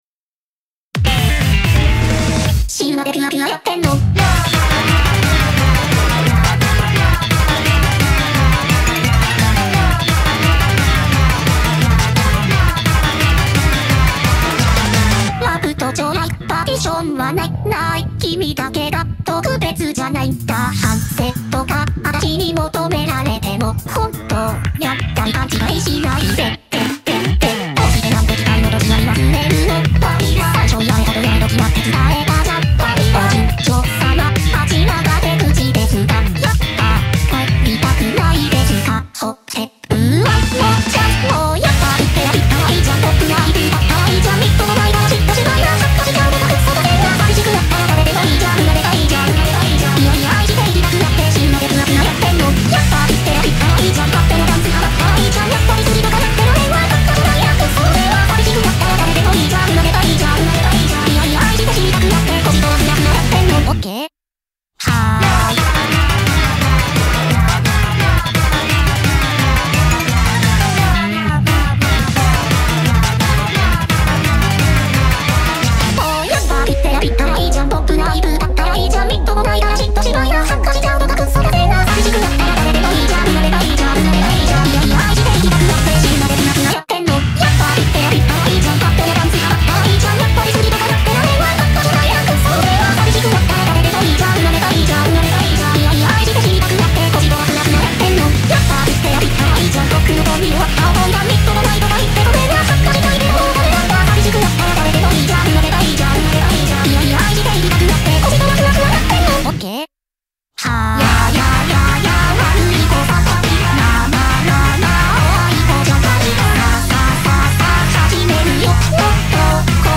BPM87-173
Audio QualityPerfect (High Quality)